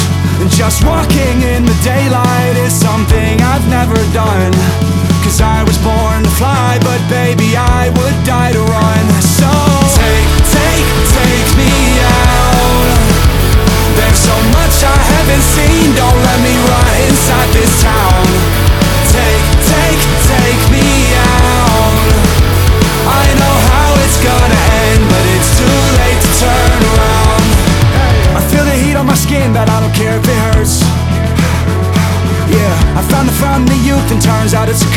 2025-07-11 Жанр: Альтернатива Длительность